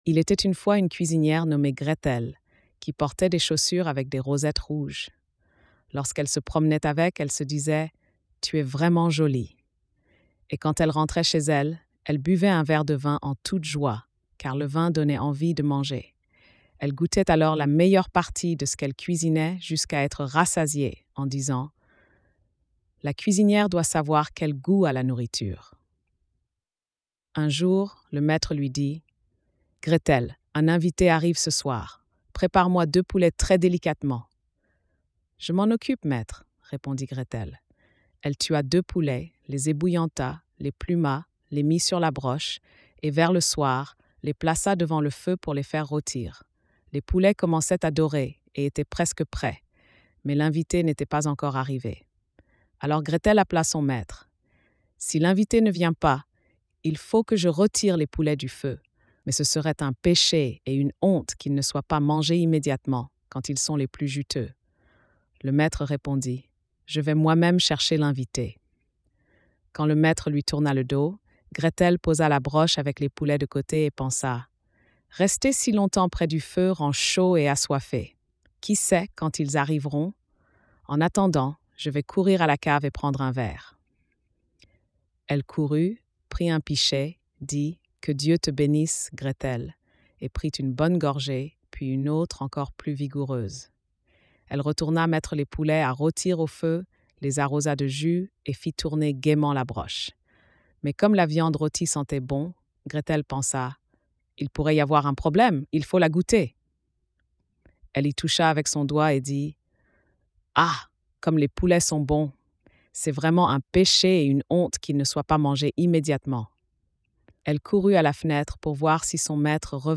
Conte de Grimm
🎧 Lecture audio générée par IA